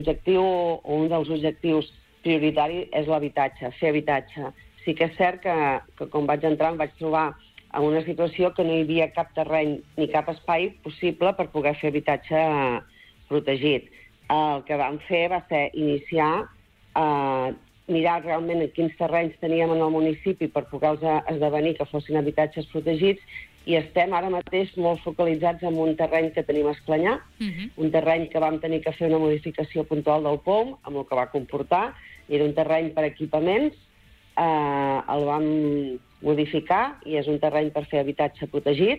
Entrevistes SupermatíSupermatí